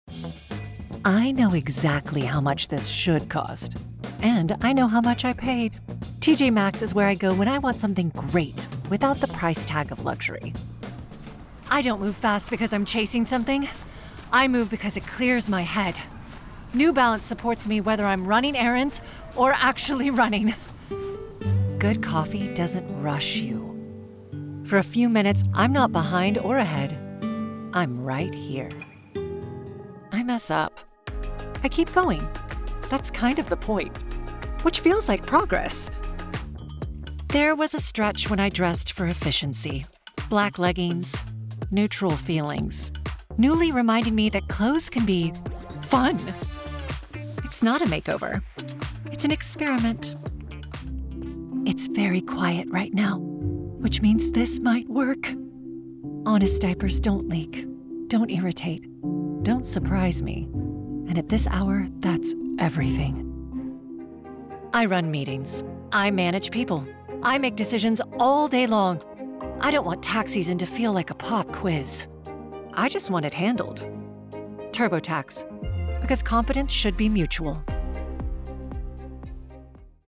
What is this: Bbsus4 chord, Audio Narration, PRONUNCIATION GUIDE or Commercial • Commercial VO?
Commercial • Commercial VO